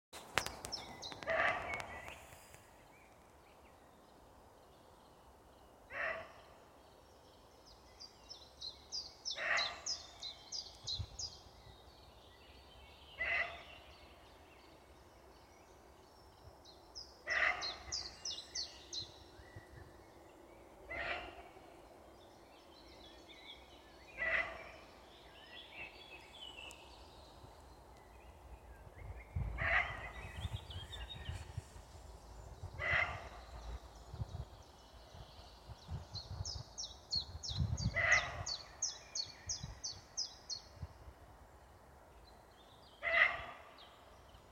Then last week I heard what from a distance sounded like a dog barking and recorded it on my phone:
but when I got closer realised it was also a roe deer, hidden somewhere in the undergrowth.